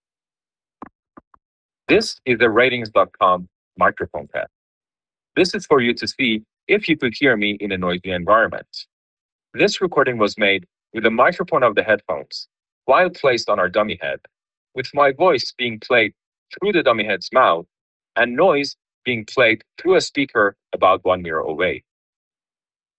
Speech + Pink Noise Audio Sample
Constant background noise is filtered out decently well, and the person on the line can still make out your voice.
The noise gate seems to work better when you pair the buds to an iPhone 16e.